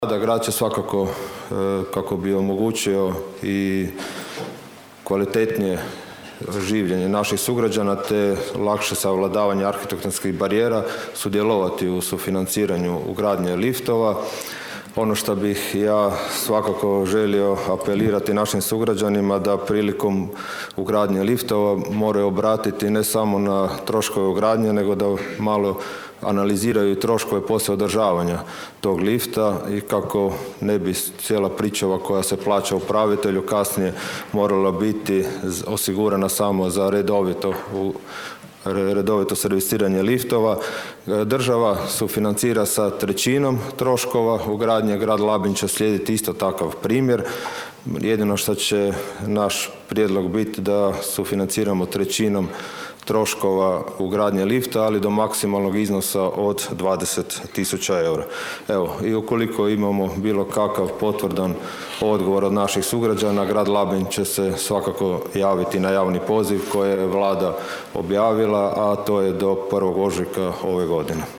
Na jučerašnjoj sjednici Gradskog vijeća Grada Labina vijećnik s Nezavisne liste Donalda Blaškovića Nino Bažon postavio je pitanje: „Hoće li se Grad Labin uključiti u sufinanciranje ugradnje liftova u višestambenim zgradama?
Gradonačelnik Donald Blašković poručio je da je, nakon što je Vlada Republike Hrvatske usvojila program ugradnje dizala u postojeće zgrade, Grad Labin zaprimio prvi upit za sufinanciranje ugradnje lifta u jednoj stambenoj zgradi na području grada te dodao: (
ton – Donald Blašković).